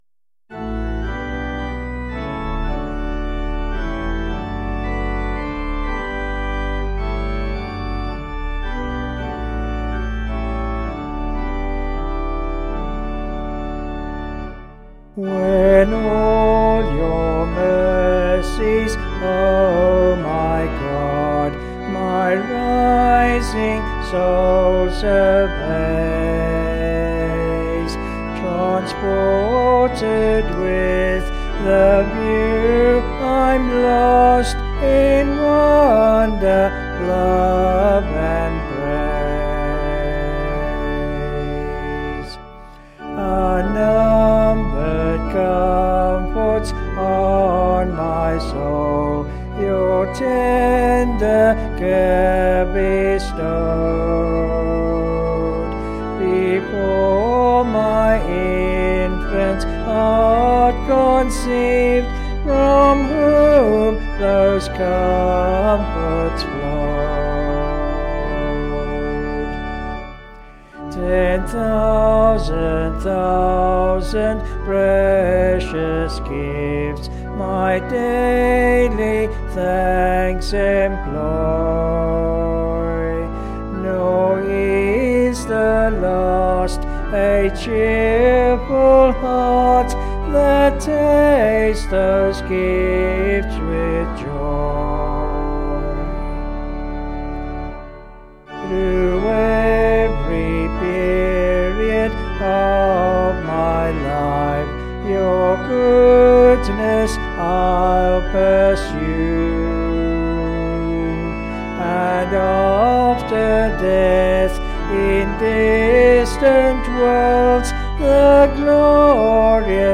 Vocals and Organ   264.5kb Sung Lyrics